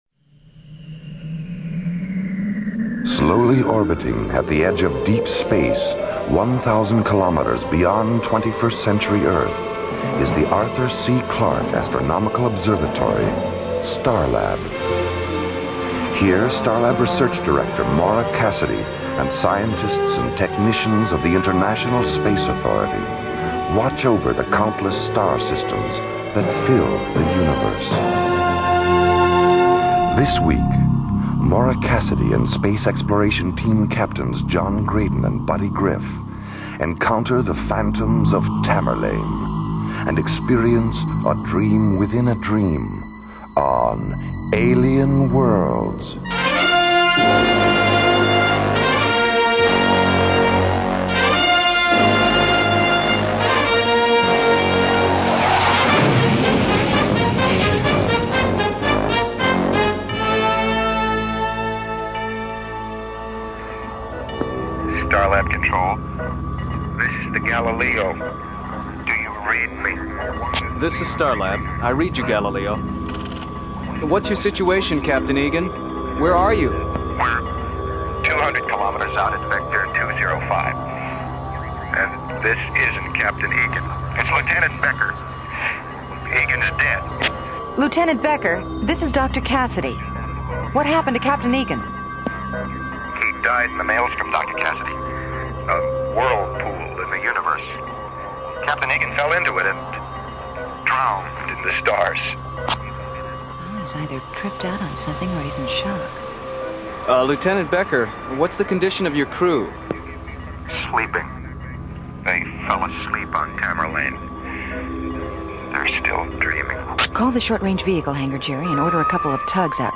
'Alien Worlds' was a syndicated radio show that brought together a blend of captivating narratives, realistic sound effects, and high production values, setting a new standard for audio drama.
The show was ahead of its time, utilizing a documentary style of dialogue that immersed listeners in its interstellar adventures.